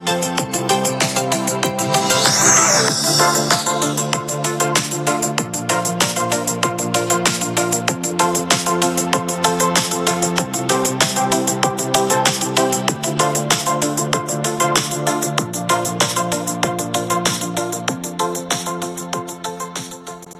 就一背景音乐网站的能用背景音，当然是识别不出来。
这种罐头音乐是挺难找的, 放弃吧